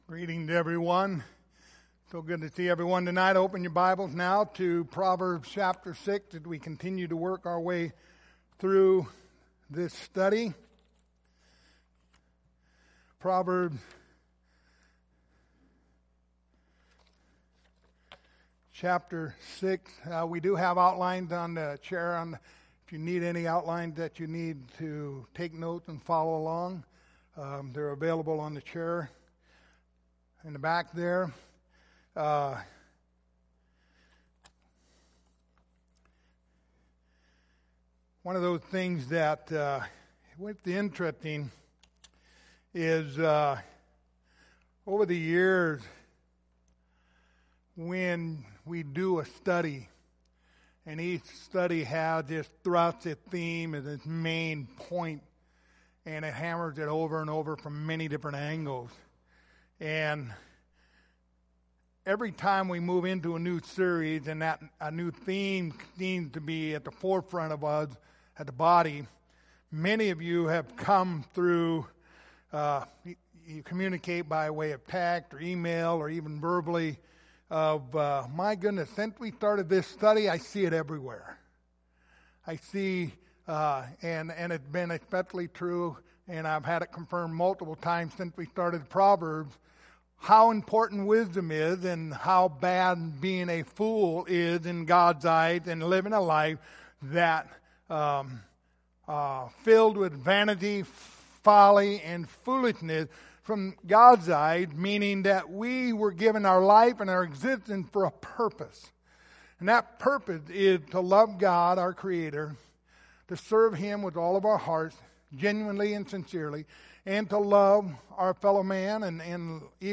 The Book of Proverbs Passage: Proverbs 6:16-19 Service Type: Sunday Evening Topics